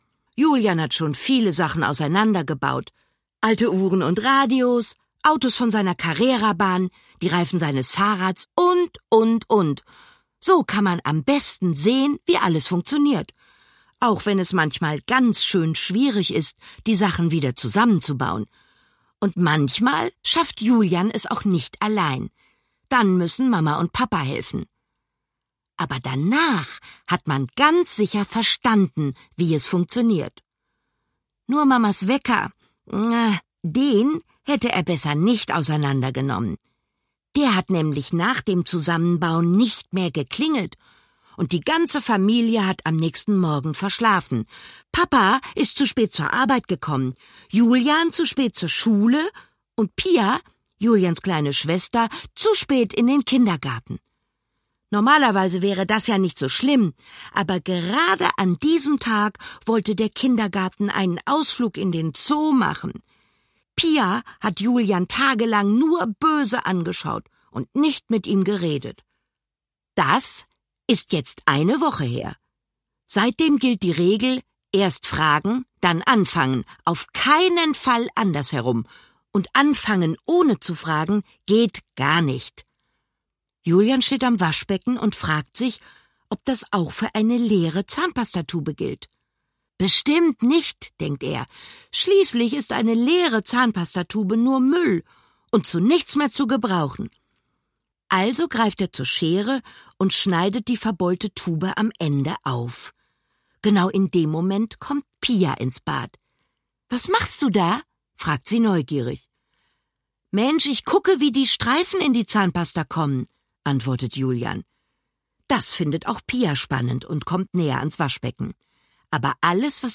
Schlagworte Geschichten • Hörbuch; Lesung für Kinder/Jugendliche • Kinderfragen • Kinder/Jugendliche: Sachbuch • Wissen • Wissen; Kindersachbuch/Jugendsachbuch